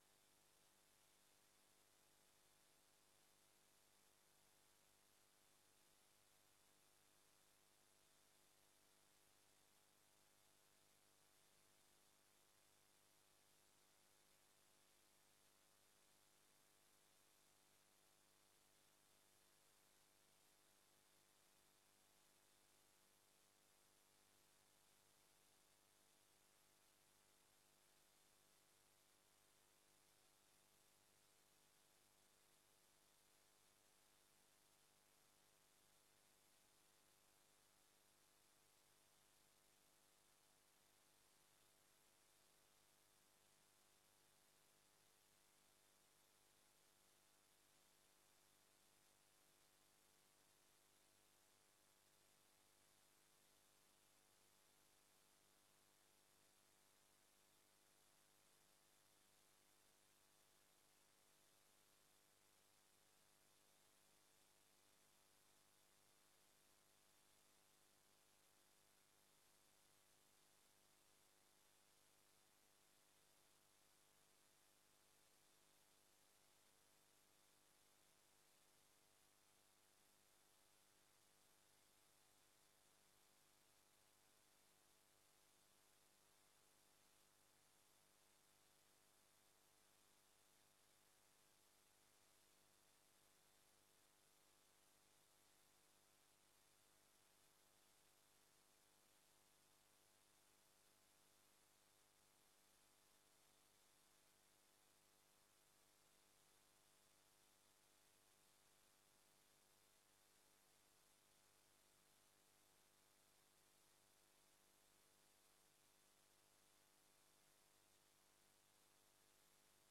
Raadscommissie gecombineerd 20 november 2025 20:00:00, Gemeente Oude IJsselstreek
DRU Industriepark - Conferentiezaal